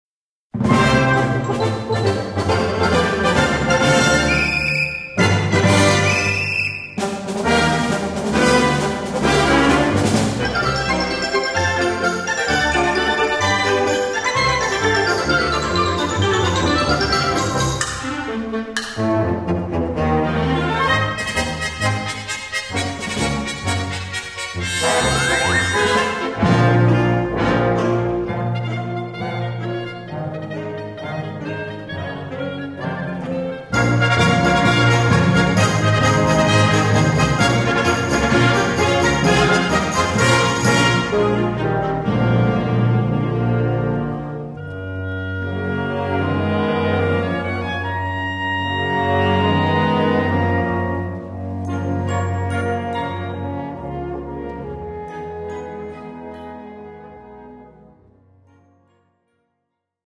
Gattung: Konzertante Blasmusik
A4 Besetzung: Blasorchester Zu hören auf